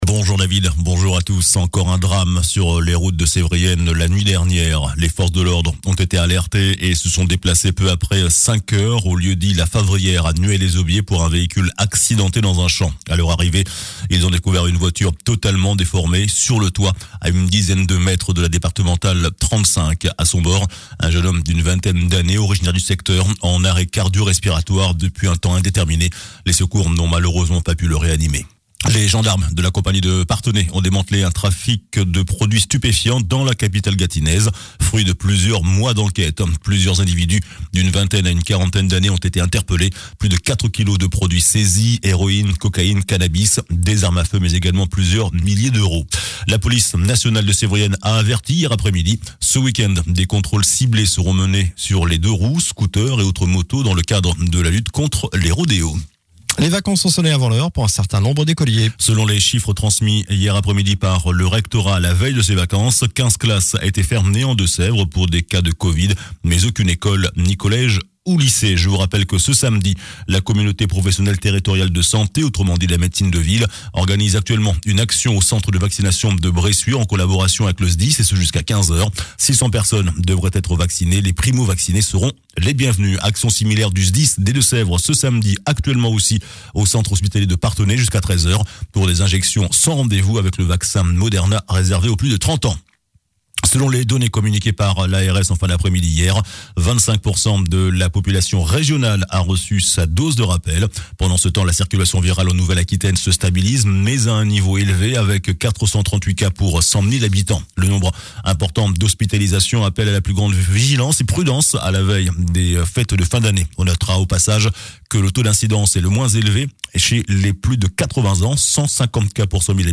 JOURNAL DU SAMEDI 18 DECEMBRE